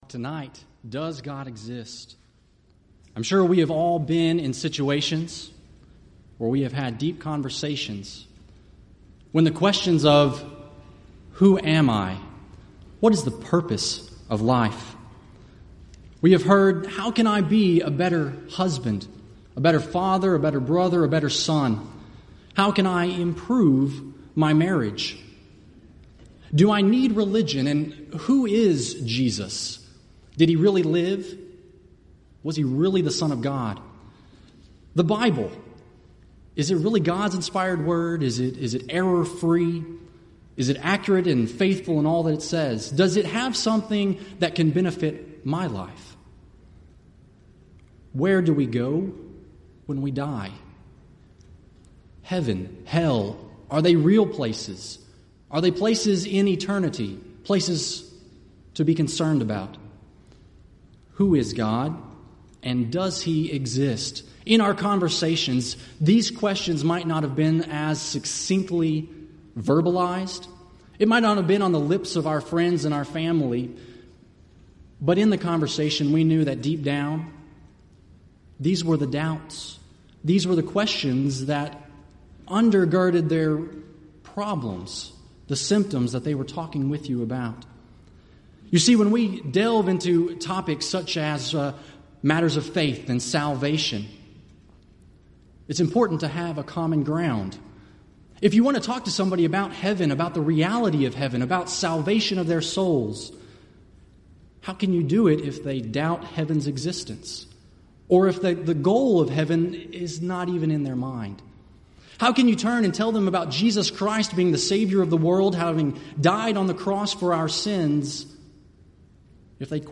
Event: 30th Annual Southwest Bible Lectures
lecture